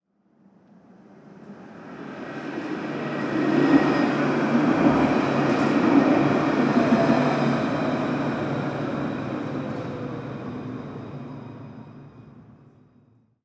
Passage de tramway #2
Passage d'un tramway parisien moderne.
Catégorie UCS : Trains / Tramway (TRNTram)
Type : Ambiance
Mode : Stéréophonique
Disposition des micros : ORTF
Conditions : Extérieur
Réalisme : Réel
Matériel : SoundDevices MixPre-3 + Neumann KM184